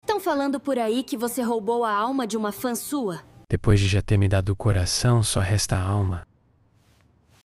entrevista com um integrante do